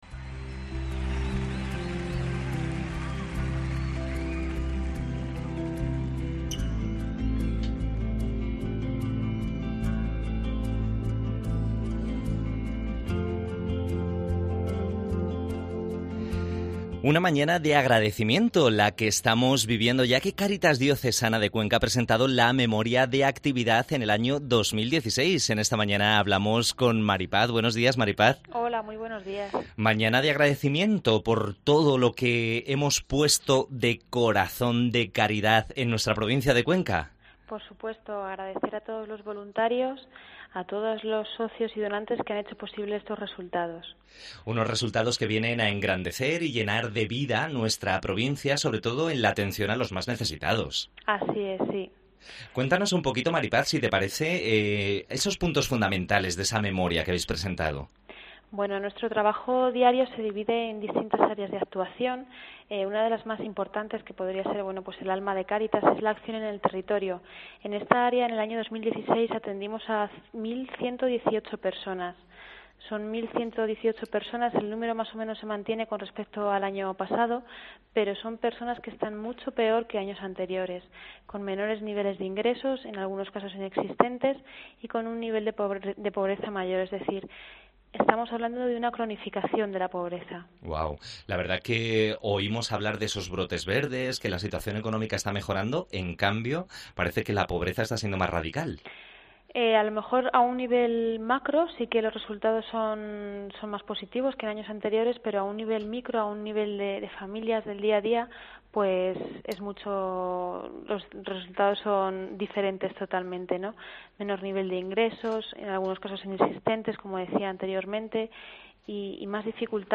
AUDIO: Entrevista acerca de la Memoria de actividades presentada por Caritas